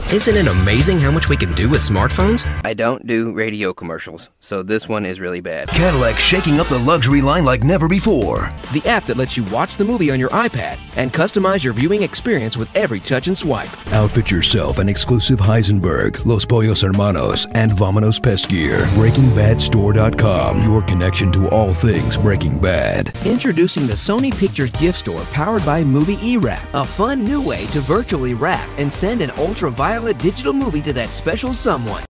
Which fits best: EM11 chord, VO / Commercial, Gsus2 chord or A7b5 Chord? VO / Commercial